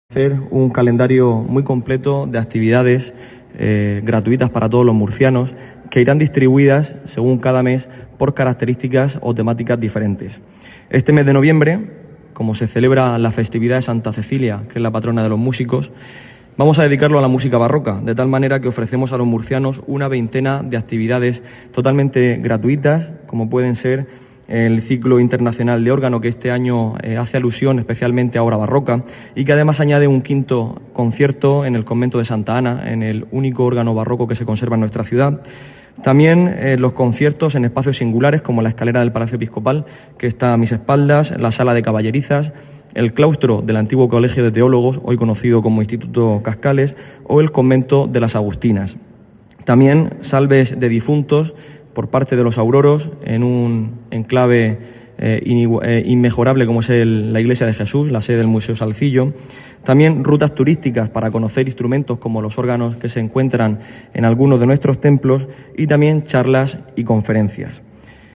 Diego Avilés, concejal de Cultura